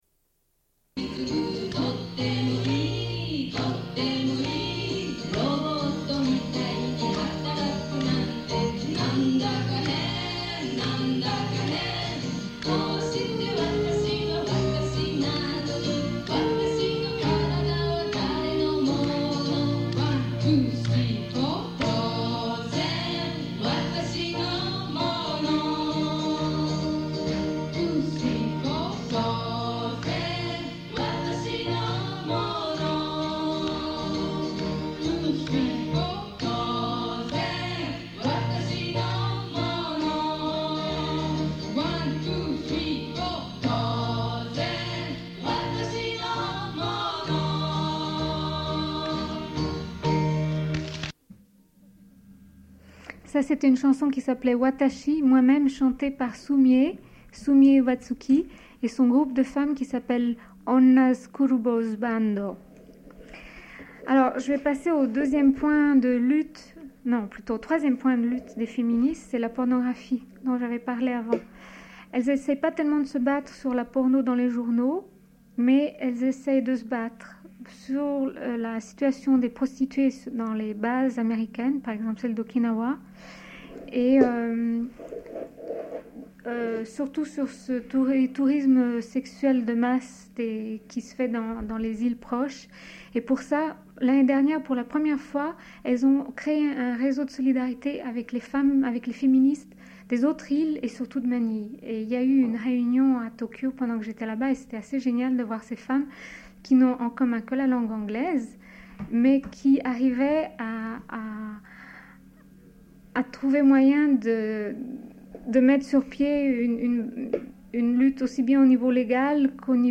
Une cassette audio, face B31:39
Suite de l'émission avec une invitée revenant du Japon qui raconte son voyage.